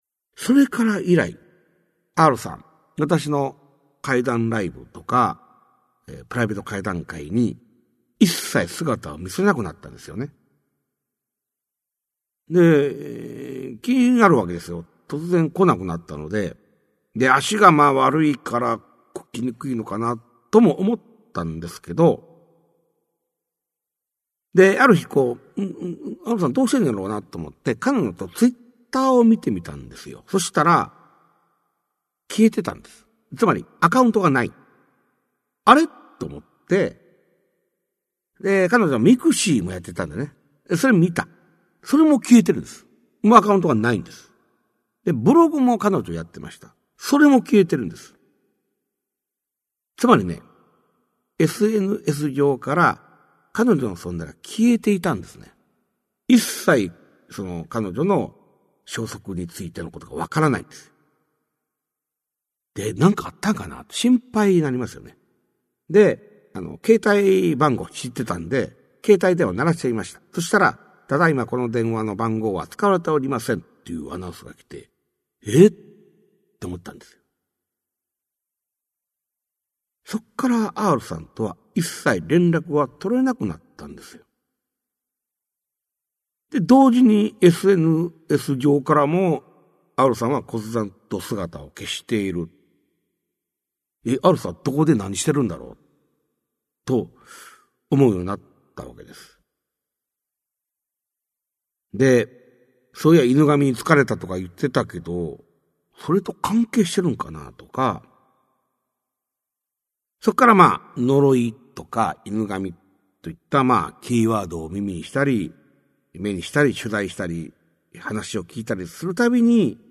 [オーディオブック] 市朗怪全集 五十七
実話系怪談のパイオニア、『新耳袋』シリーズの著者の一人が、語りで送る怪談全集! 1990年代に巻き起こったJホラー・ブームを牽引した実話怪談界の大御所が、満を持して登場する!!